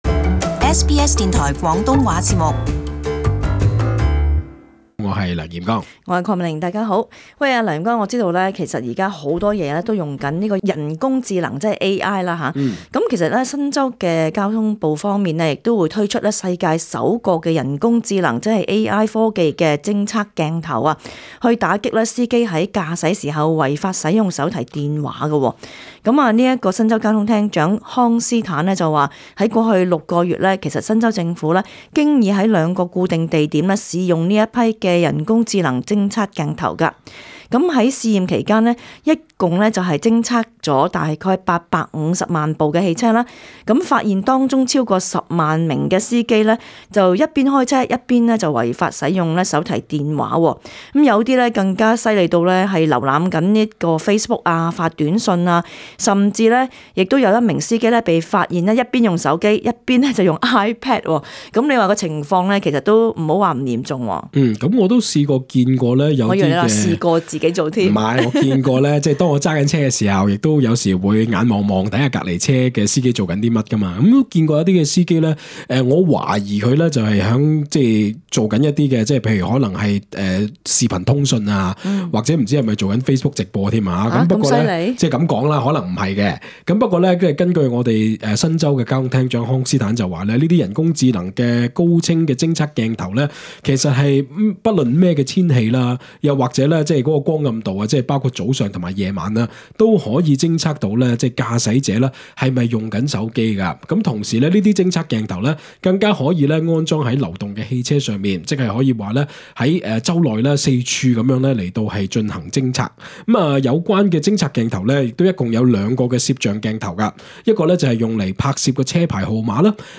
本節目內嘉賓及聽眾意見並不代表本台立場 READ MORE 【大眾論壇】如何應對汽油價格高企？